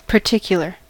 particular: Wikimedia Commons US English Pronunciations
En-us-particular.WAV